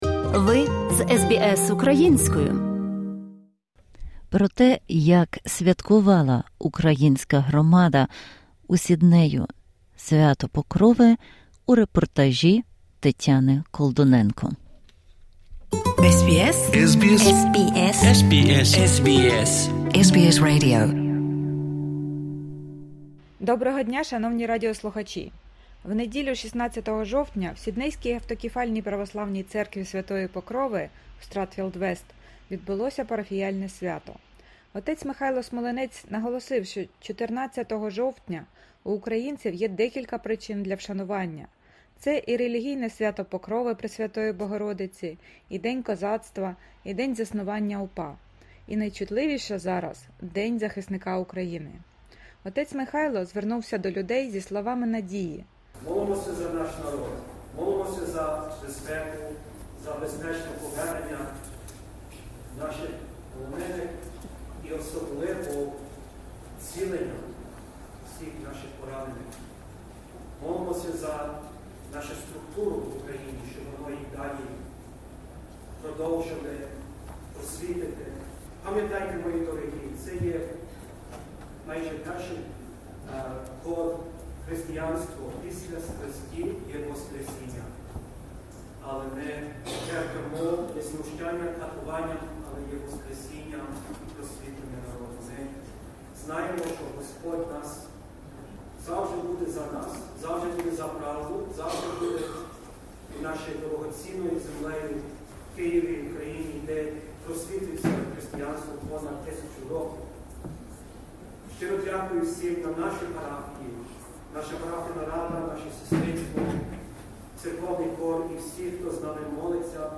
У неділю, 16 жовтня, в сіднейській автокефальній православній церкві Святої Покрови в Стратфілд Вест відбулося Парафіяльне свято.